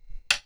hitWood2.wav